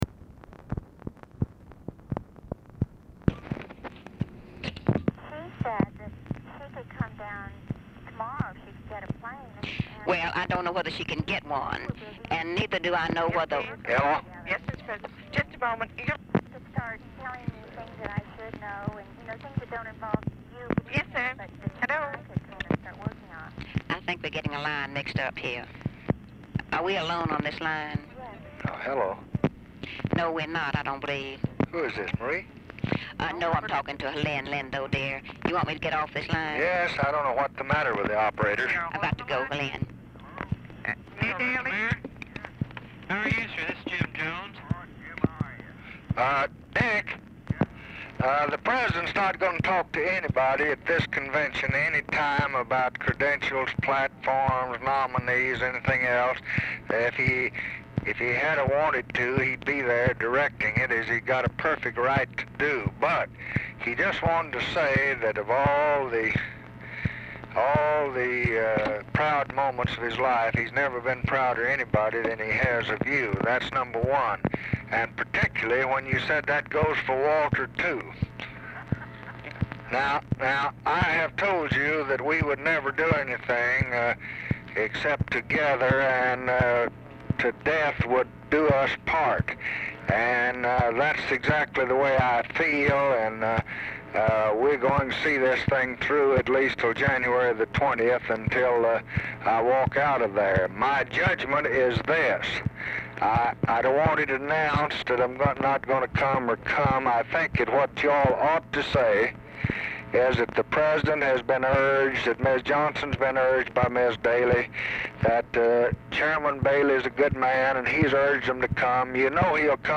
Telephone conversation # 13326, sound recording, LBJ and RICHARD DALEY, 8/27/1968, 3:47PM
POOR SOUND QUALITY; DALEY IS ALMOST INAUDIBLE
Dictation belt